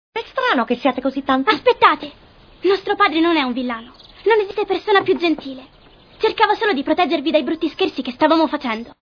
vocegfranc.mp3